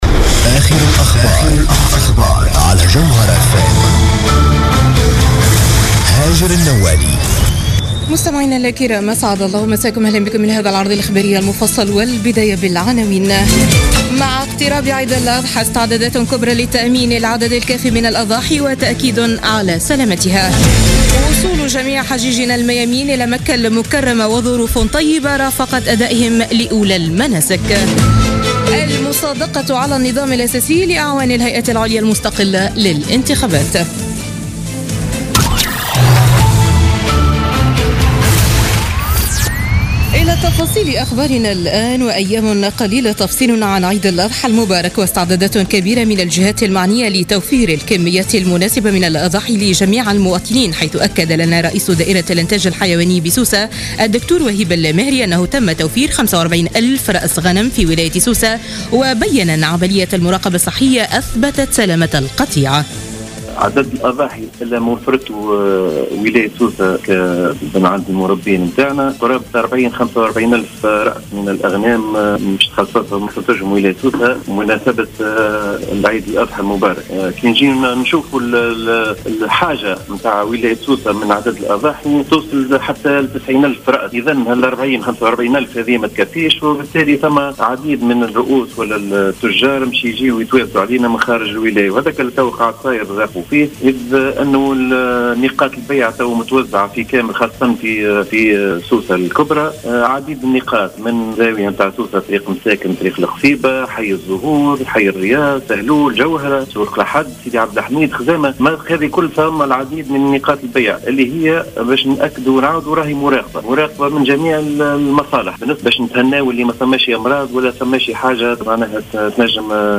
نشرة أخبار السابعة مساء ليوم الأربعاء 7 سبتمبر 2016